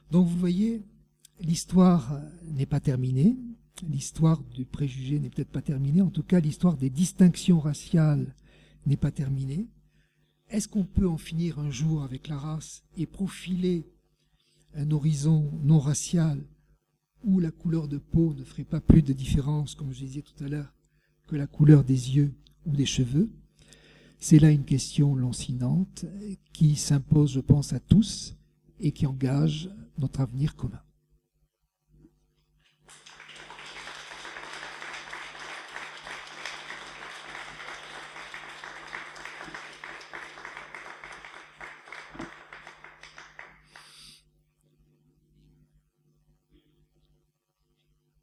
Cette conférence se veut une reflexion anthropologique qui engage le passe et le présent